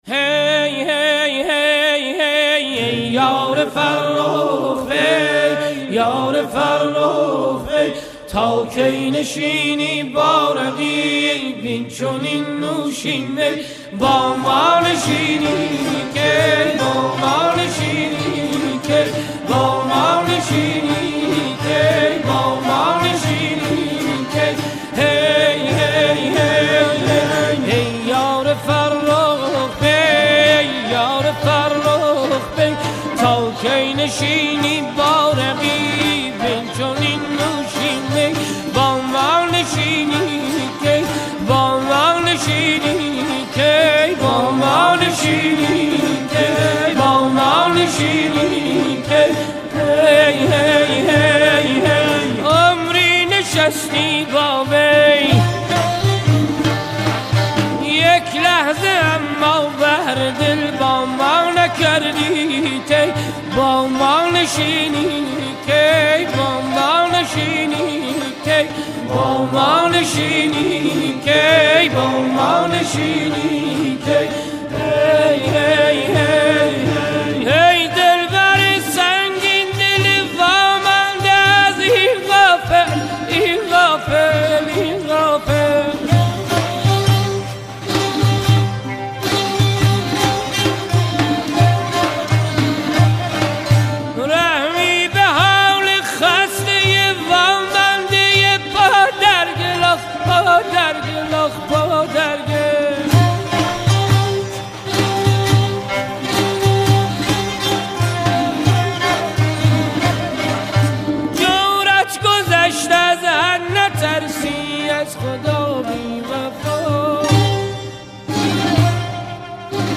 اهنگ سنتی